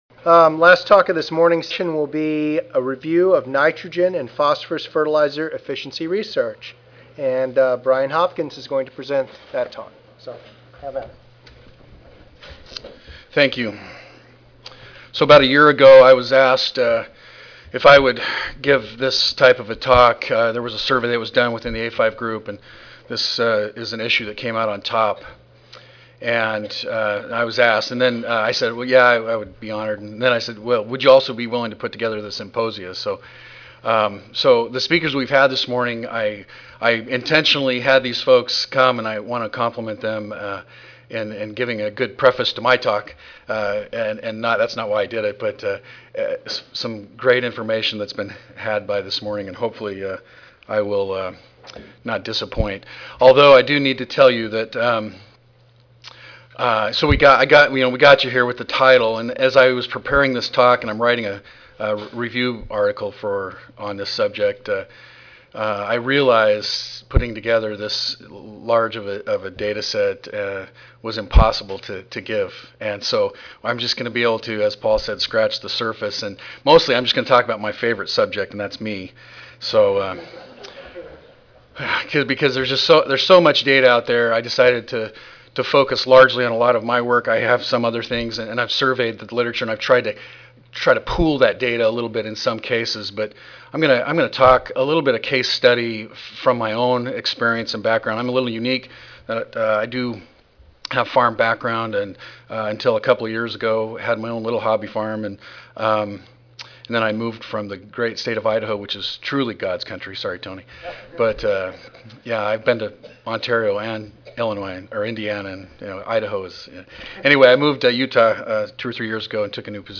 UT Audio File Recorded presentation Abstract